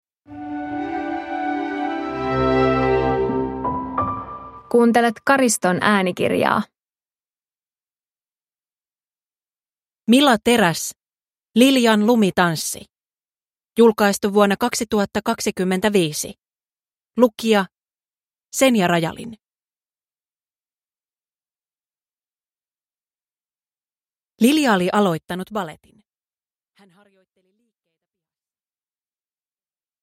Liljan lumitanssi – Ljudbok